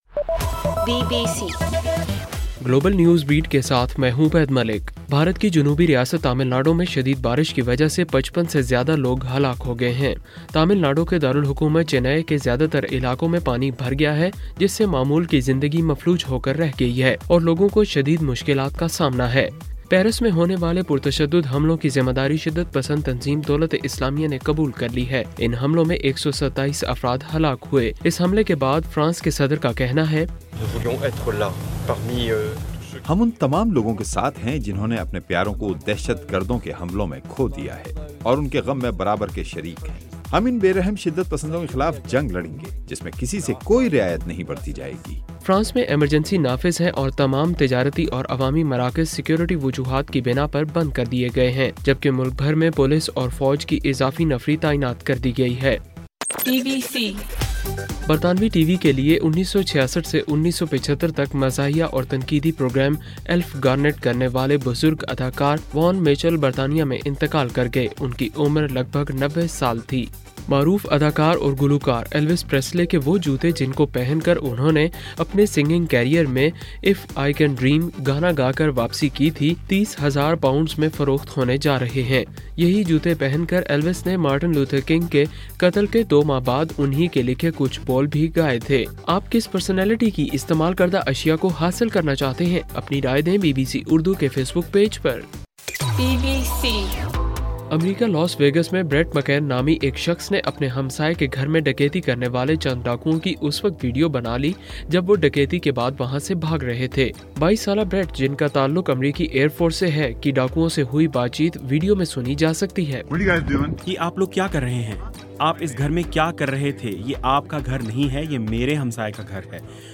نومبر 14: رات 11 بجے کا گلوبل نیوز بیٹ بُلیٹن